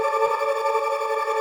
SaS_MovingPad05_170-A.wav